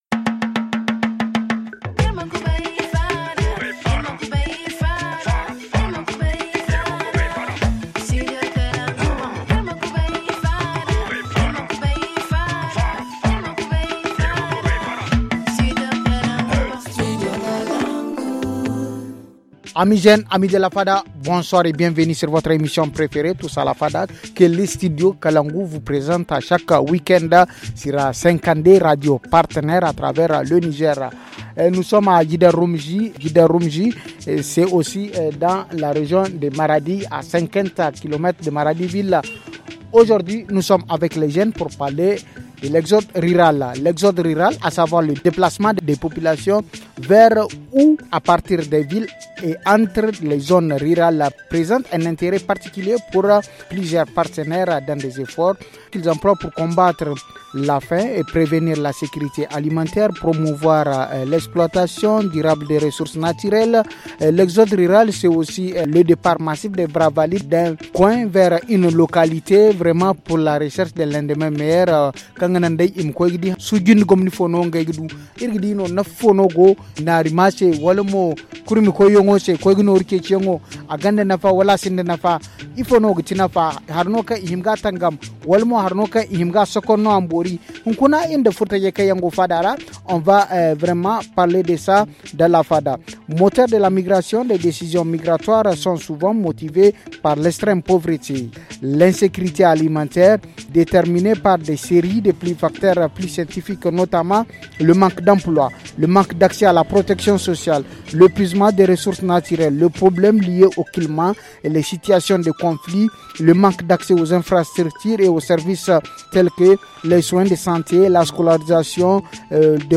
Pour en parler, l’équipe jeune a fait le déplacement jusqu’au département guidan Roumjdi, region de Maradi une localité qui connaît un mouvement massif des jeunes vers les autres régions du Niger :